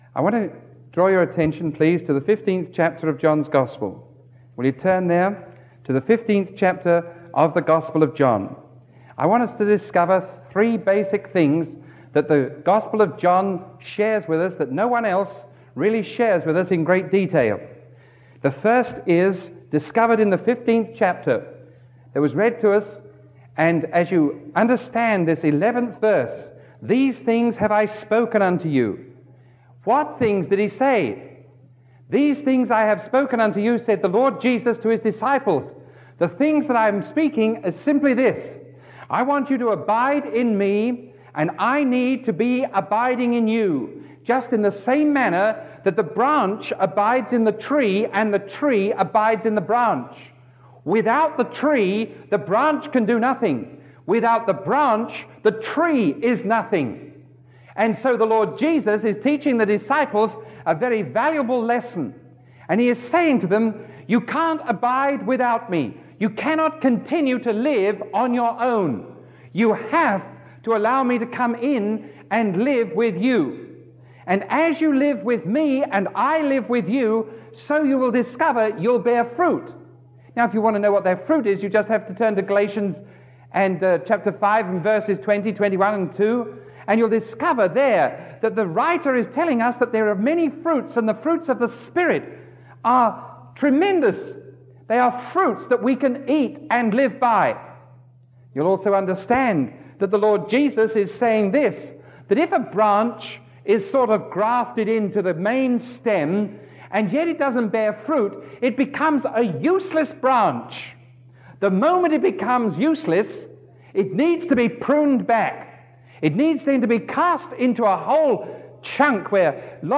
Sermon 0201A recorded on July 27